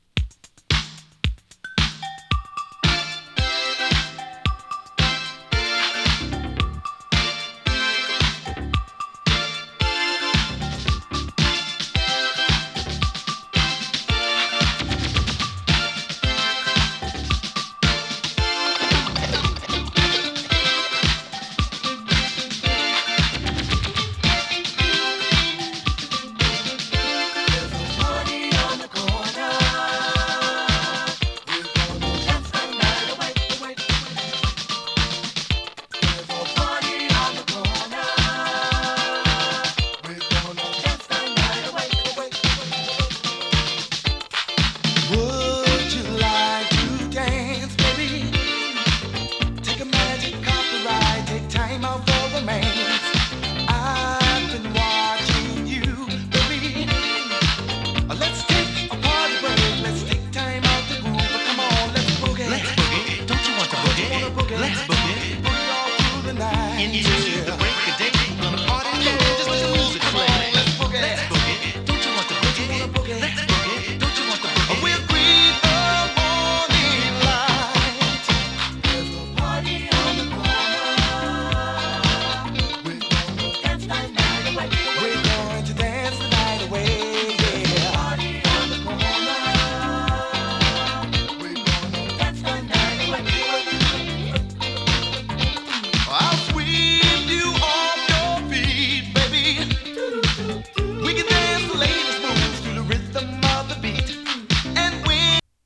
エレクトロなオールドスクール・テイストのディスコ・ブギー！